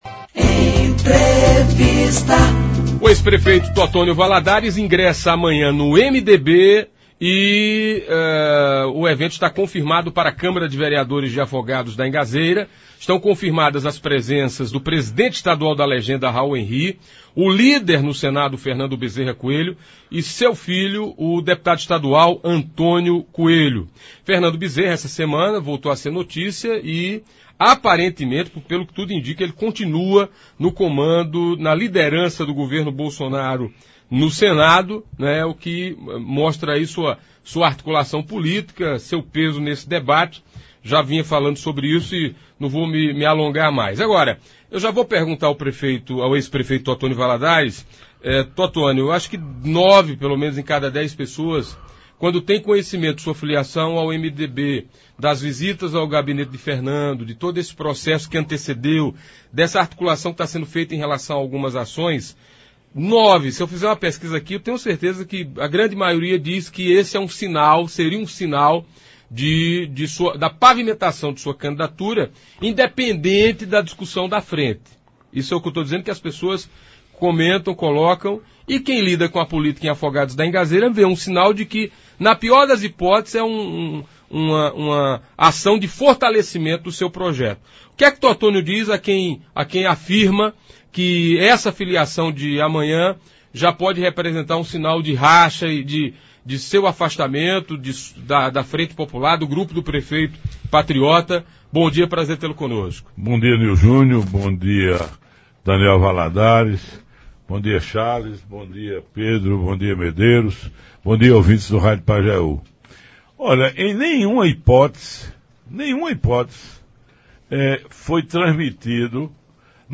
Participando do programa Manhã Total da Rádio Pajeú FM desta sexta-feira (27), o ex-prefeito e pré-candidato à Prefeitura de Afogados da Ingazeira, Totonho Valadares que já governou o município em três oportunidades.